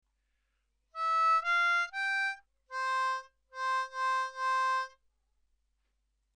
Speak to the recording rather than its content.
Now let’s learn the chunks.